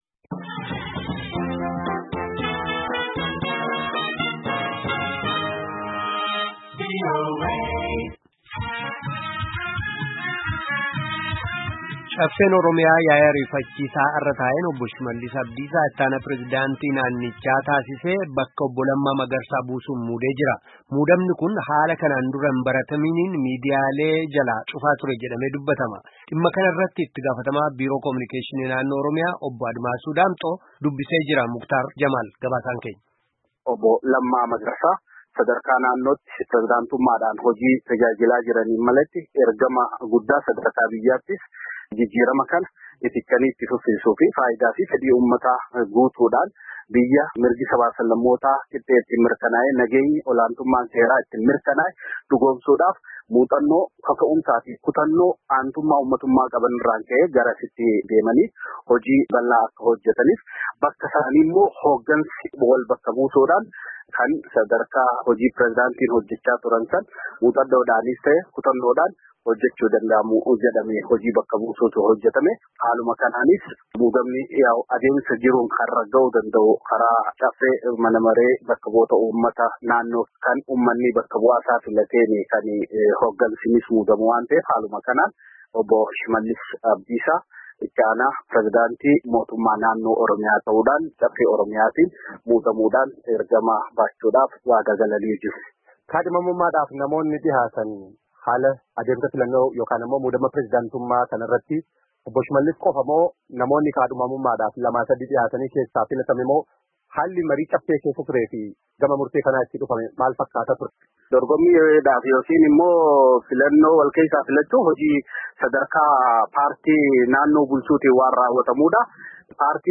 Itti-gaafatamaa Biiroo Komiyunikeeshinii Naannoo Oromiyaa - Obbo Admaasuu Daamxoo dubbisnee jira.